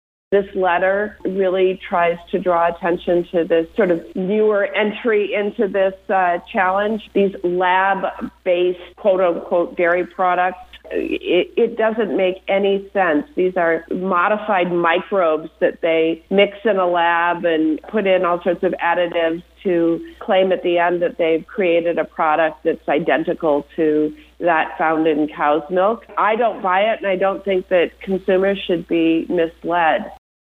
Senator Tammy Baldwin, a Wisconsin Democrat, says the effort seeks to get FDA to “do its job”.
Audio with Senator Tammy Baldwin (D-WI)